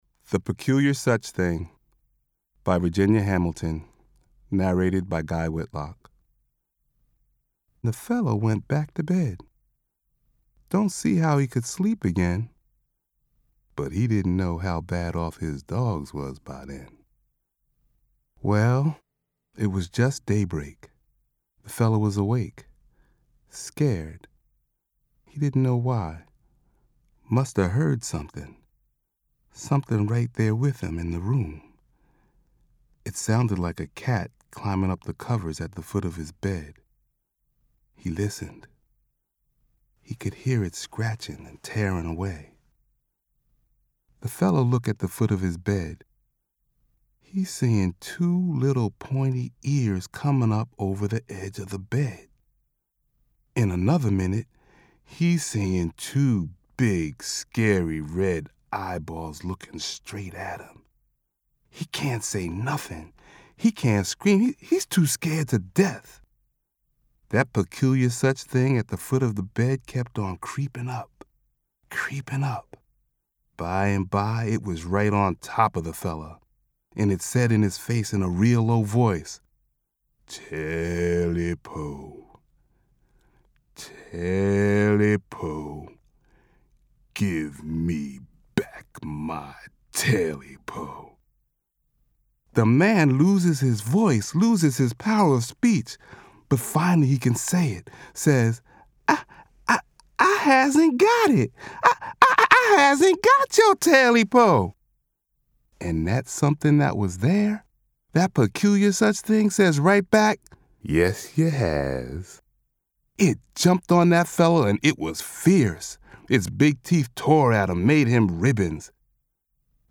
Sample the versatile voiceover talent